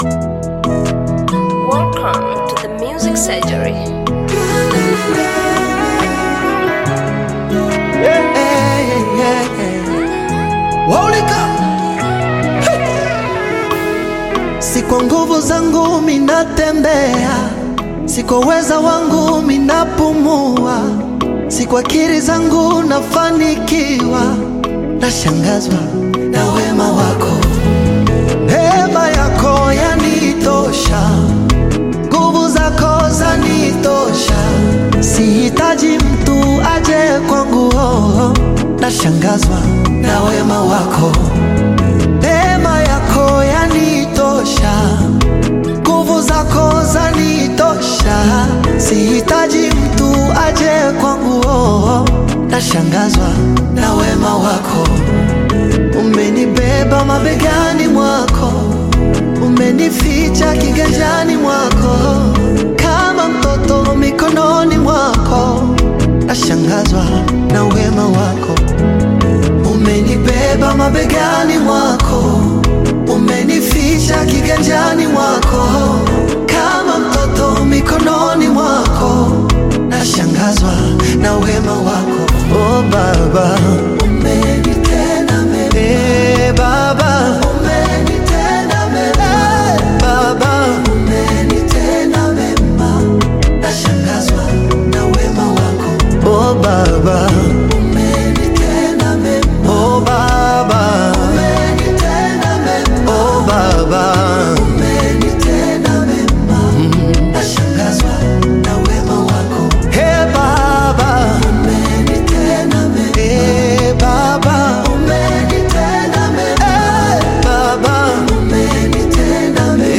Gospel music track
Gospel song
This catchy new song